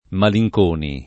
Malinconi [ mali j k 1 ni ] cogn.